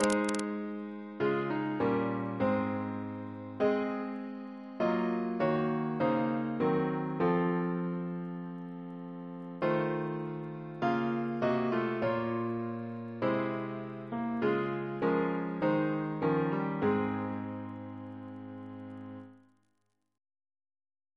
Double chant in A♭ Composer: Chris Biemesderfer (b.1958)